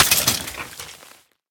Minecraft Version Minecraft Version snapshot Latest Release | Latest Snapshot snapshot / assets / minecraft / sounds / mob / bogged / death.ogg Compare With Compare With Latest Release | Latest Snapshot
death.ogg